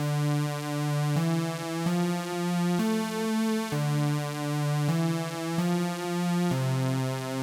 BAL Synth Riff D-E-F-A-C.wav